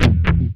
GUITARFX 8-L.wav